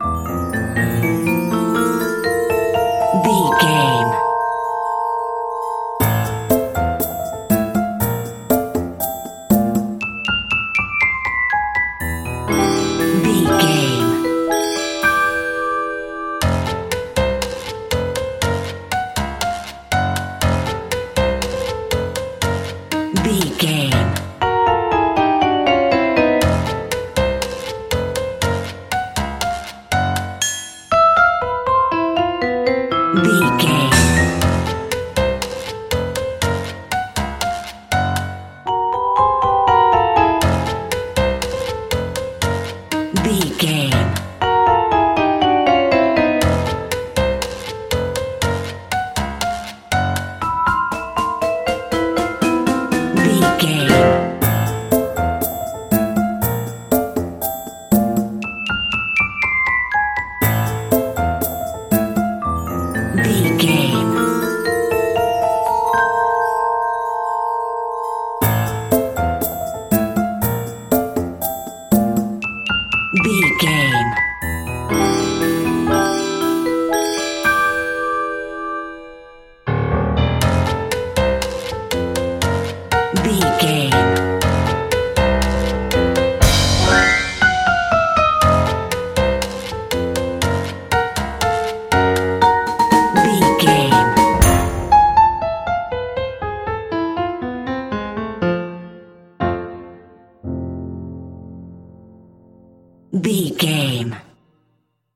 Dorian
percussion
piano
silly
circus
goofy
comical
cheerful
perky
Light hearted
quirky